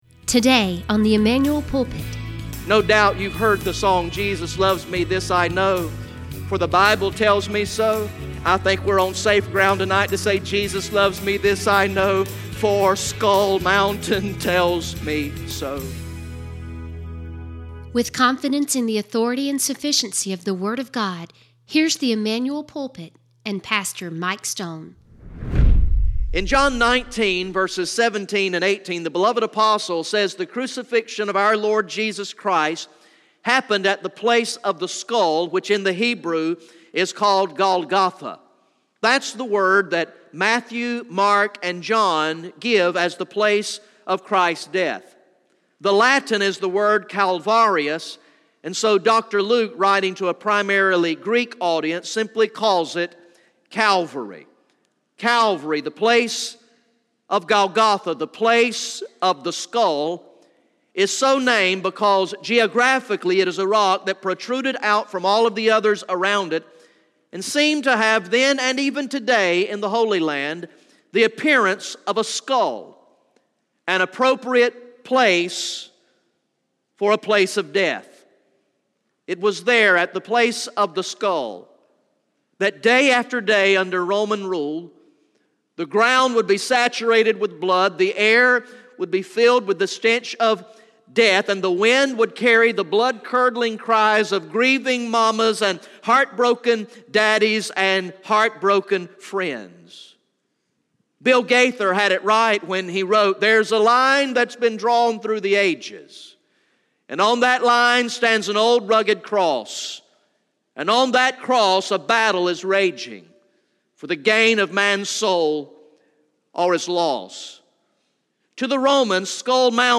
From the evening worship service on Sunday, February 25, 2018